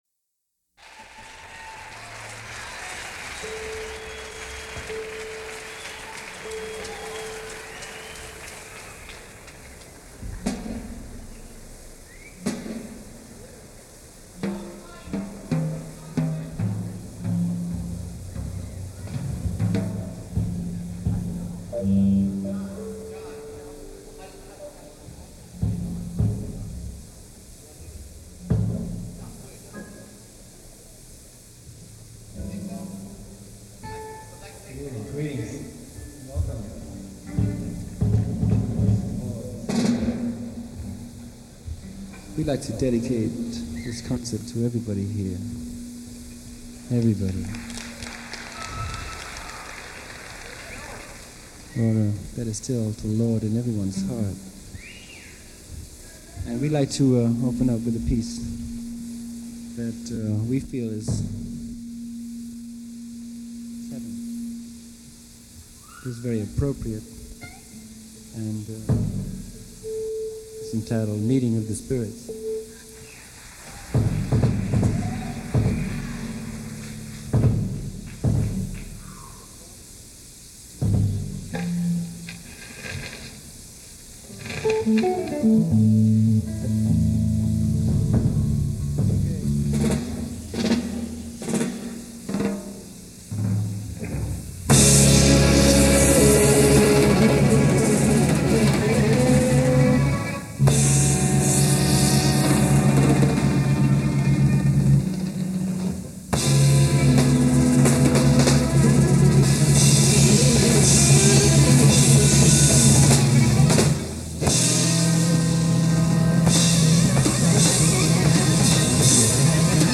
Recorded at Symphony Hall, Boston.
in concert this weekend